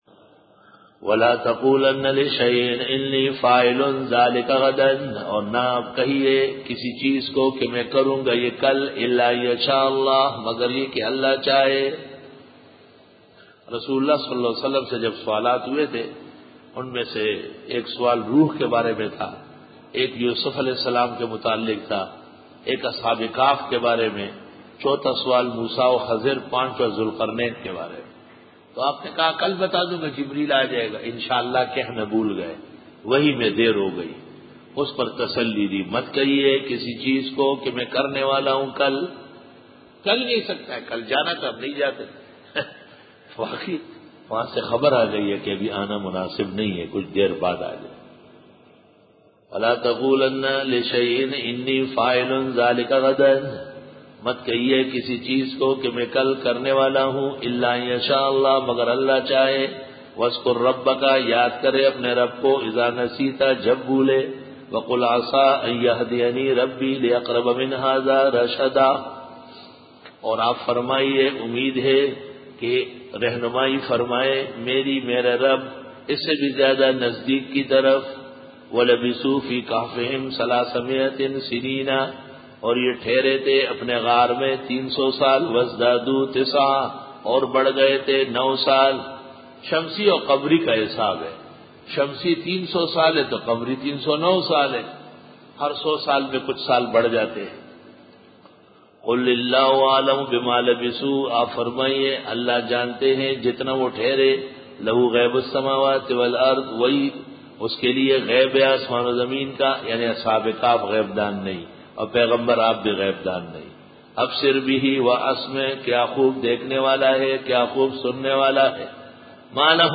سورۃ الکھف رکوع-04 Bayan